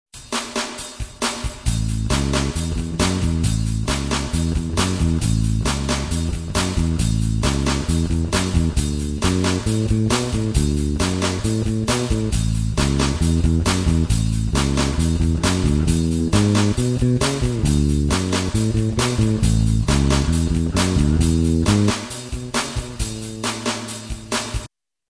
Je vous propose des pages consacrées à différents styles avec à chaque fois des petits clips audio de 30s à 2 mn avec un accompagnement d'un coté et la basse de l'autre illustrant plusieurs types de lignes de basse simples et répétitives comme illustration de ce qu'il est possible de jouer dans un contexte donné.
blues classique
blues.wav